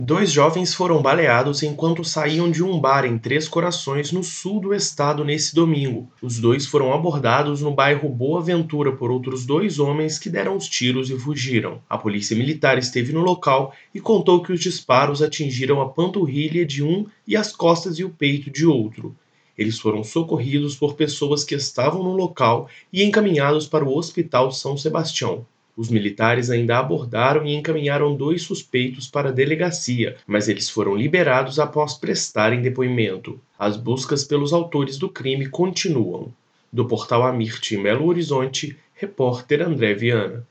AMIRT Notícias em áudio Policial Sul de MinasThe estimated reading time is less than a minute